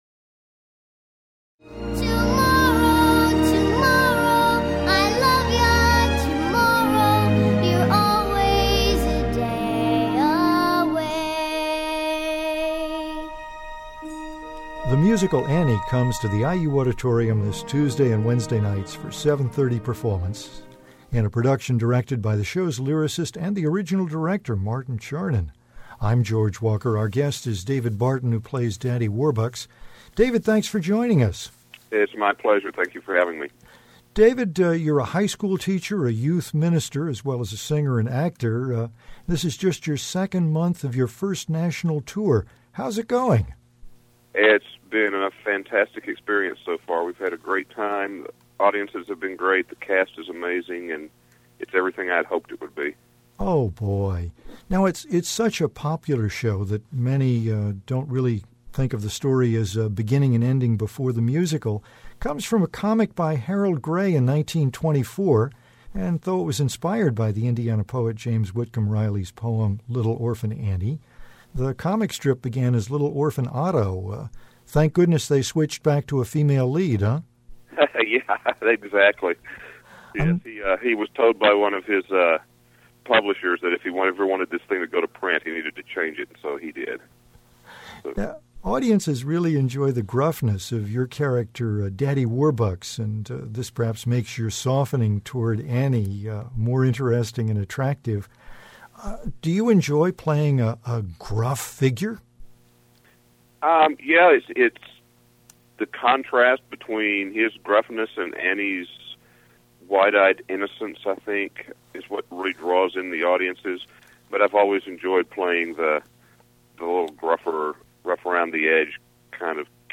talks with actor